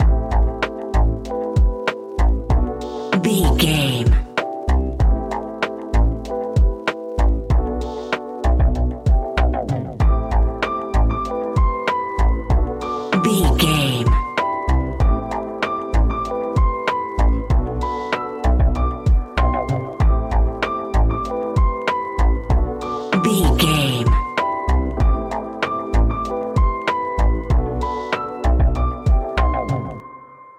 Ionian/Major
A♭
chilled
laid back
Lounge
sparse
new age
chilled electronica
ambient
atmospheric